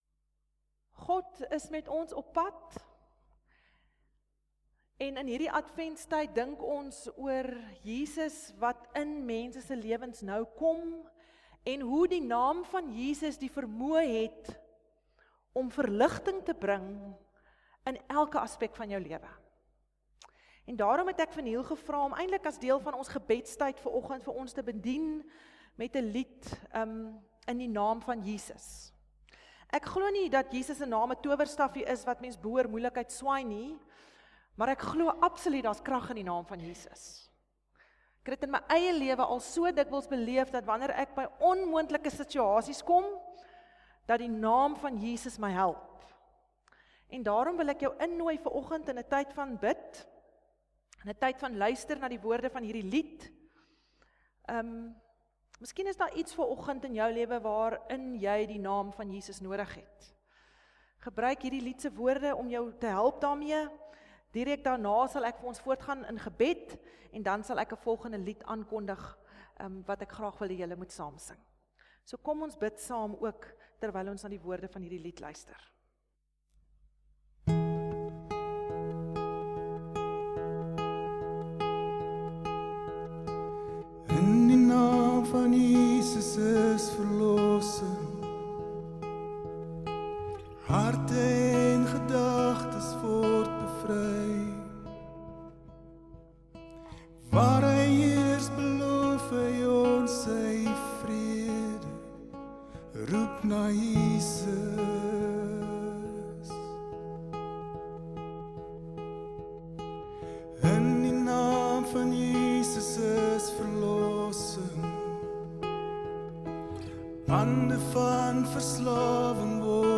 Oggenddiens - 8 Desember 2019